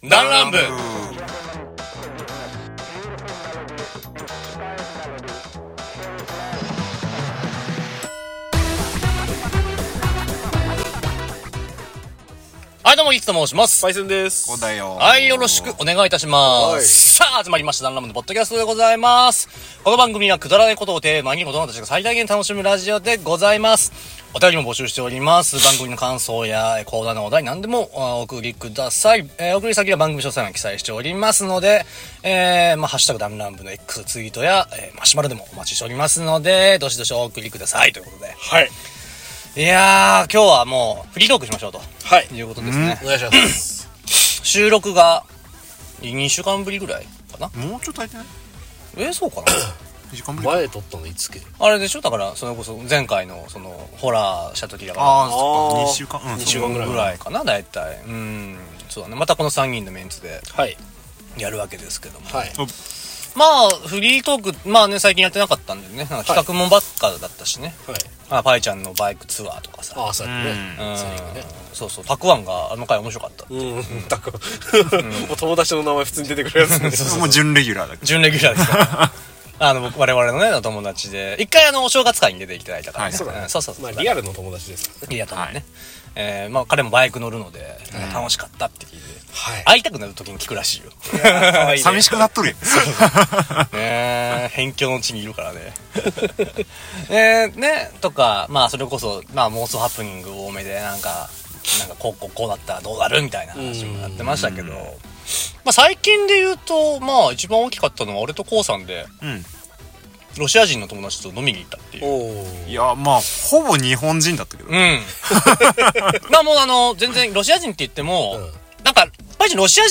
だんらん部 -アラサー男達の勝手なる雑談会-
くだらない ことを 最大限 に楽しむラジオ番組 何人で、何の話をするかはいつもバラバラ。